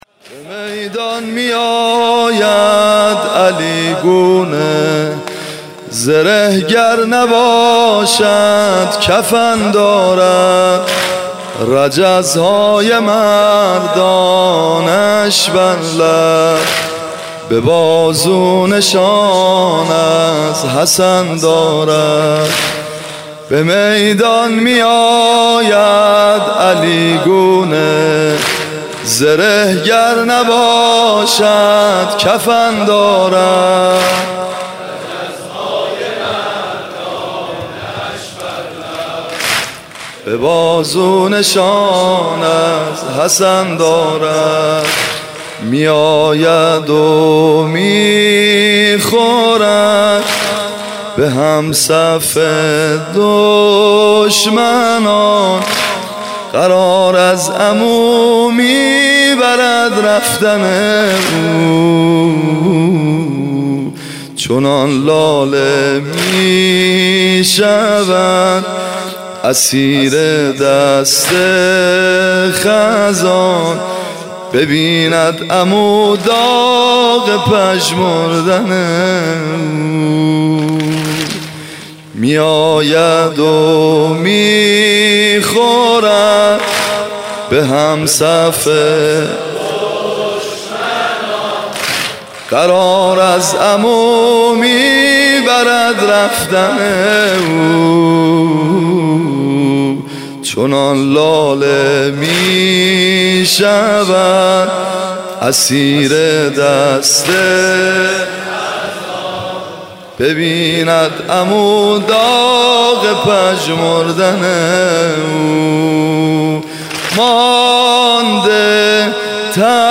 شب ششم محرم95/ هیئت ابن الرضا(ع)
زمینه شب ششم محرم